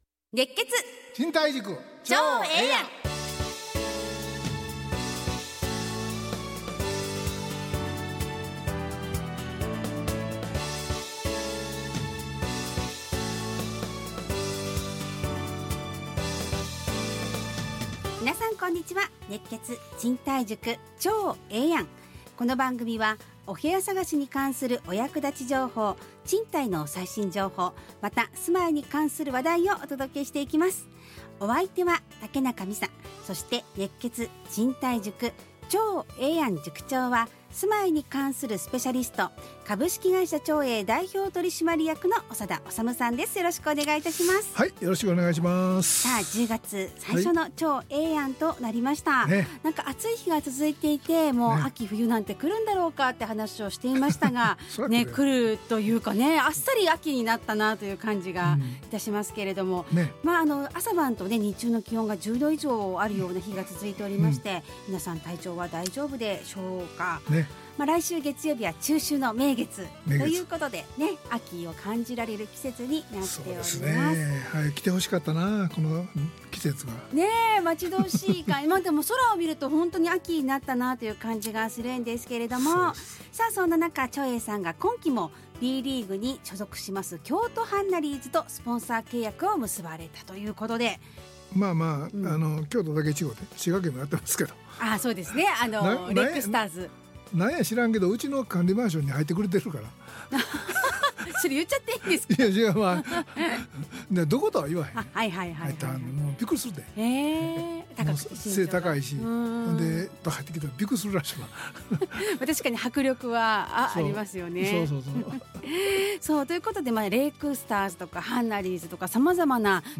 ラジオ放送 2025-10-03 熱血！